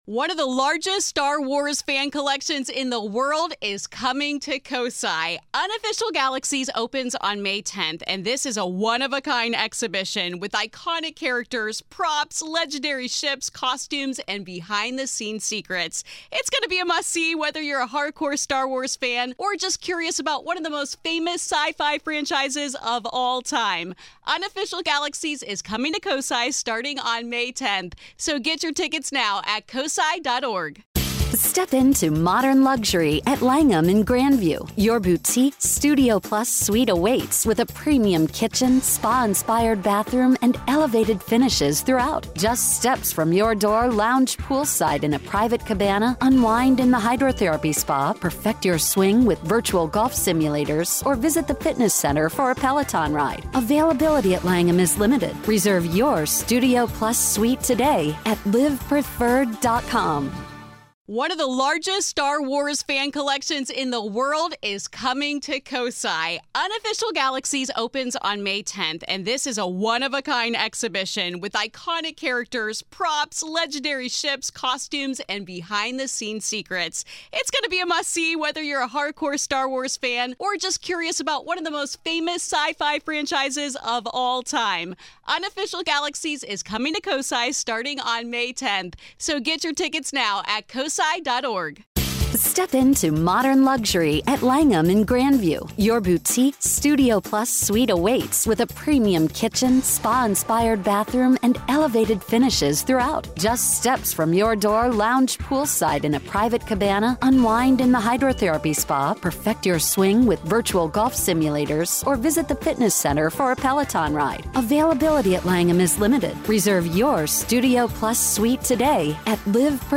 This is Part Two of our conversation